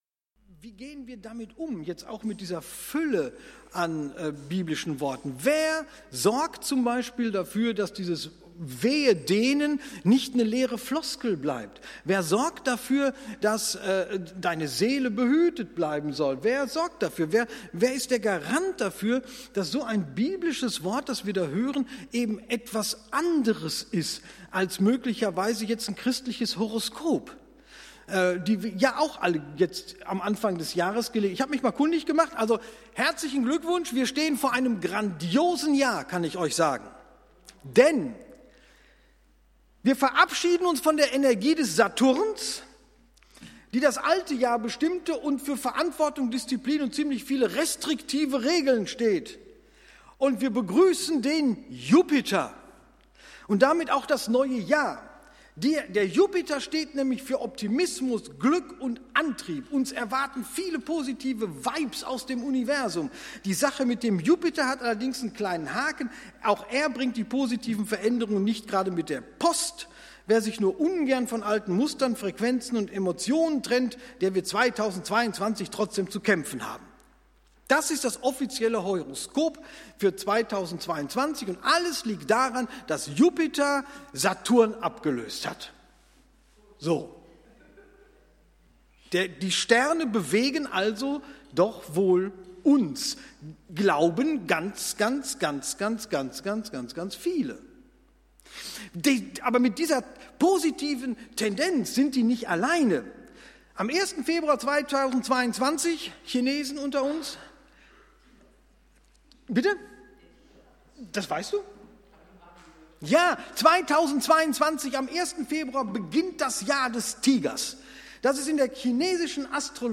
Gottesdienst
Jan. 17, 2022 | Predigten | 0 Kommentare